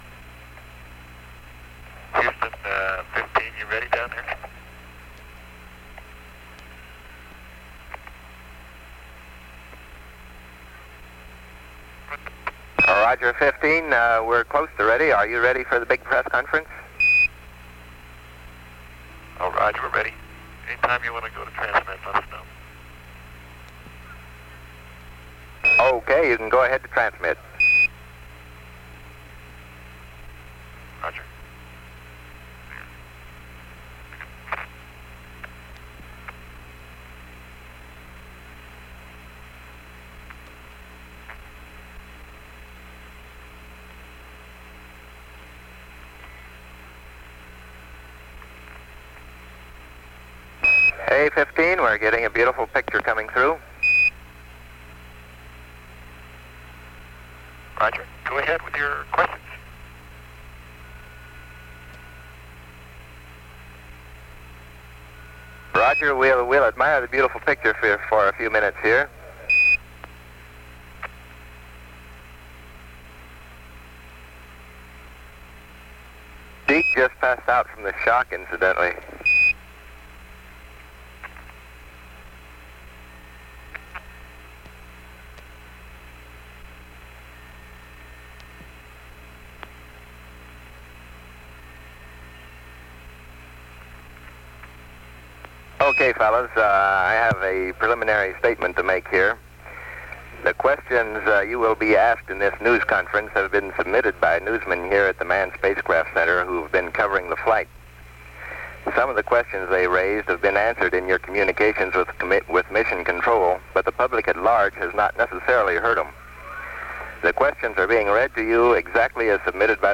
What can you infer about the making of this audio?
TV transmission from the spacecraft begins at 270:22:14 as the crew settle down in front of the camera for a press conference.